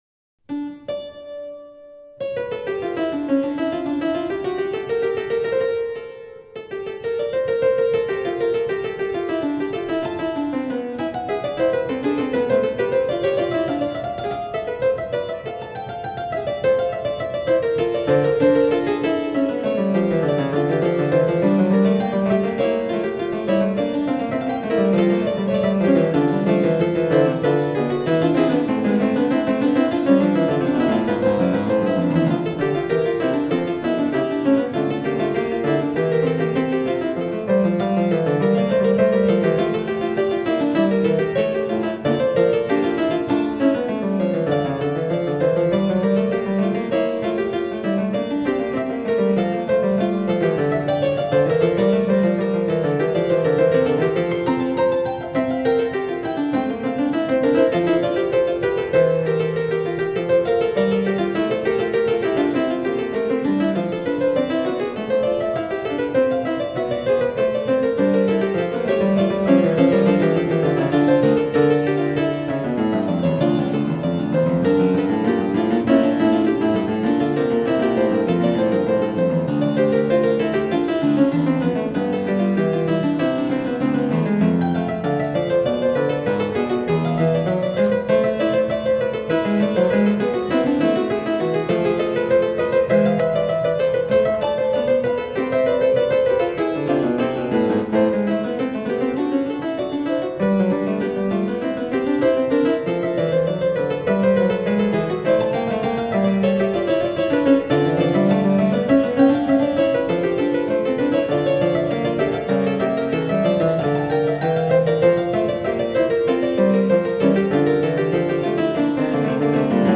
Hammerflügel